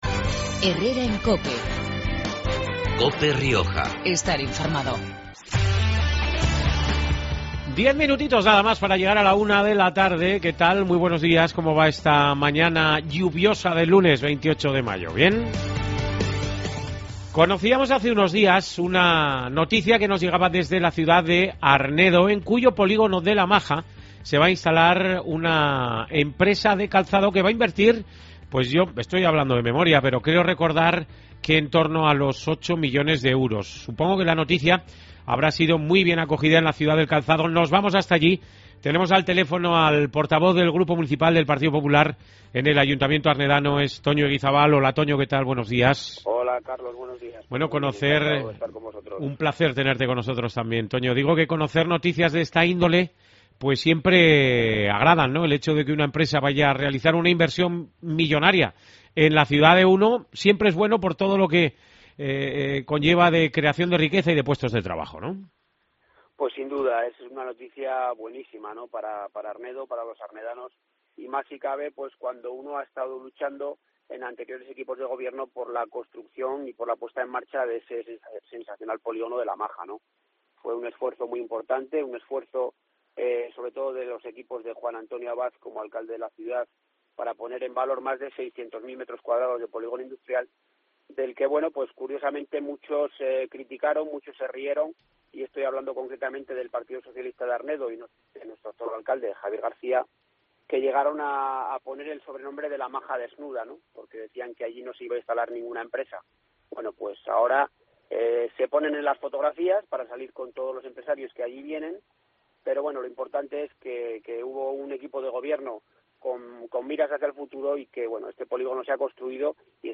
La noticia ha sido muy bien acogida en la ciudad y, según recordaba este mediodía en Cope Rioja Toño Eguizábal, portavoz del PP en el Ayuntamiento, ha sido posible gracias a la apuesta que en su día hizo el que fuera alcalde popular, Juan Antonio Abad.